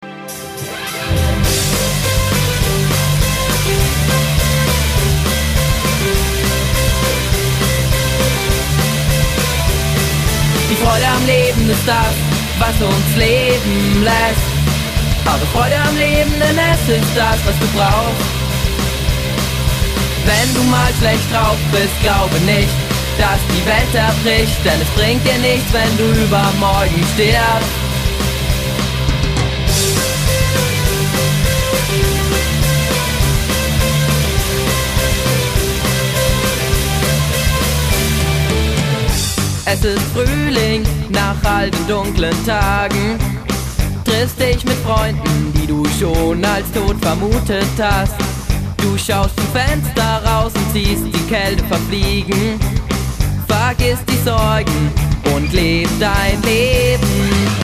Voc, Git
Bass, Tasten
Schlagzeug